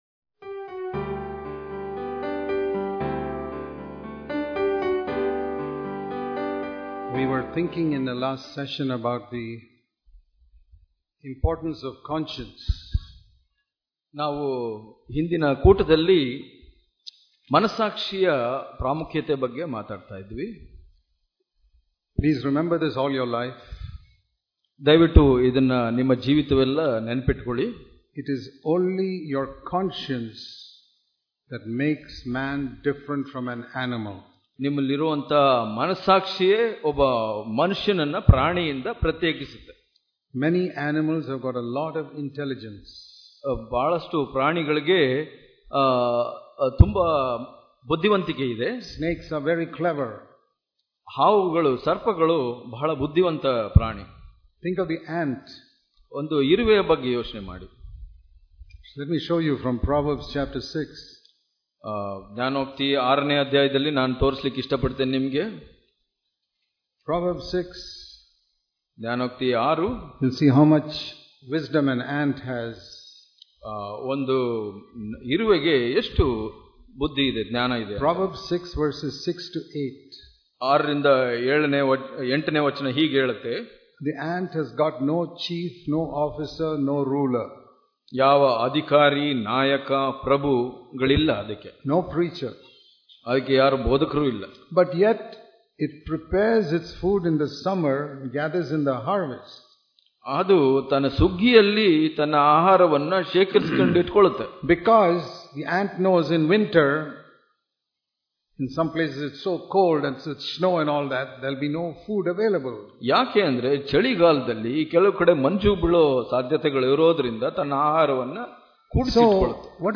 ಇಂದಿನ ಧ್ಯಾನ